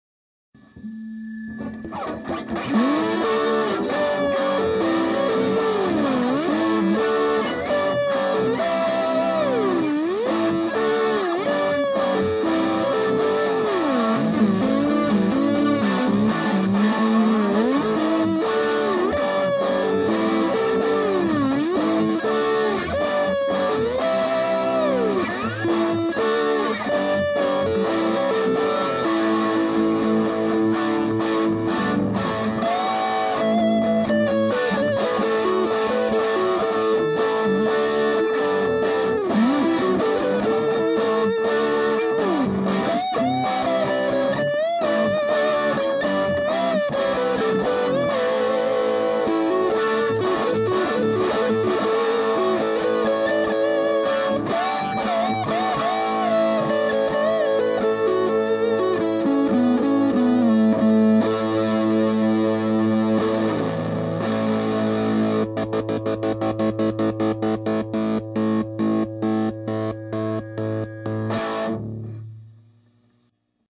They were recorded using an SM57 into a computer using Sonar. No effects or eq added.
First clip is a Les Paul. Tone and volume settings on 12:00 but the gain settings are full up. The first part of the lead is with slide and then it moves to standard lead. Lead is with the neck pickup, while the rhythm is all bridge pickup.